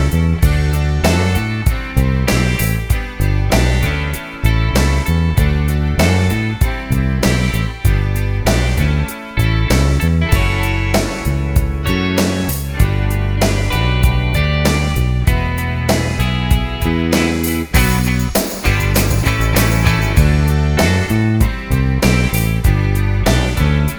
no Backing Vocals Indie / Alternative 3:50 Buy £1.50